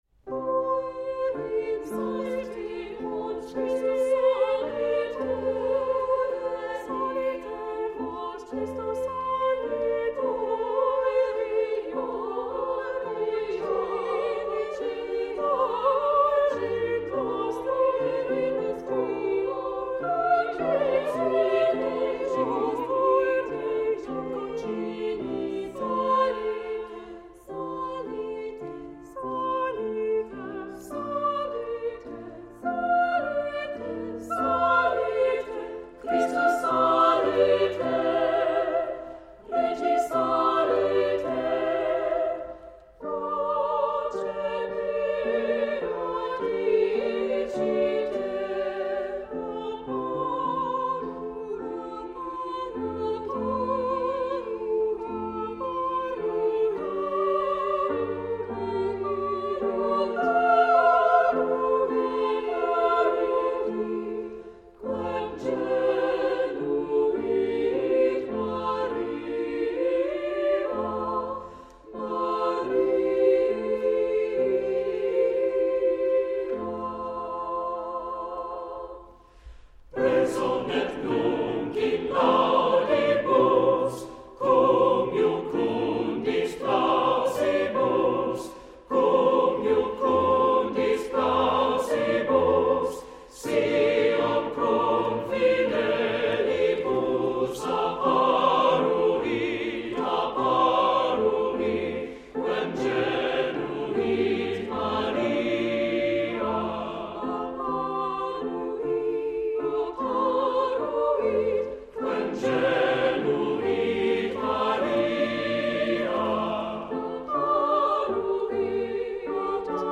Voicing: SSAATTB